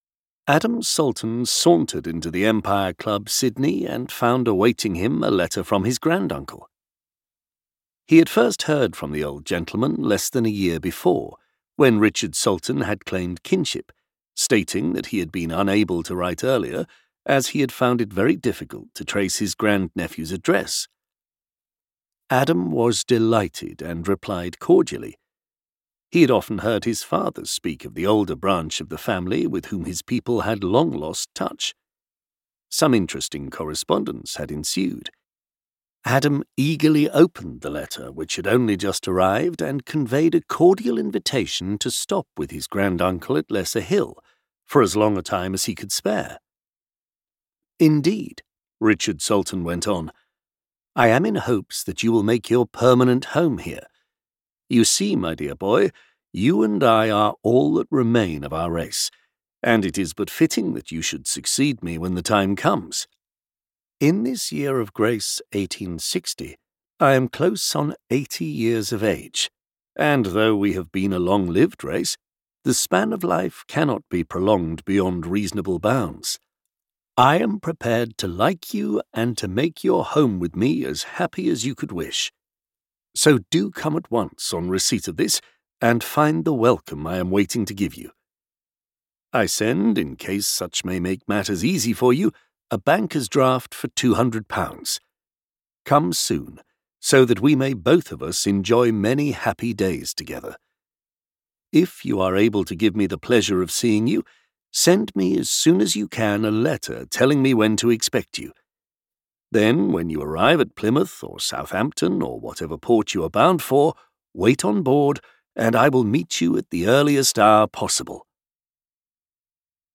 Audio knihaThe Lair of the White Worm
Ukázka z knihy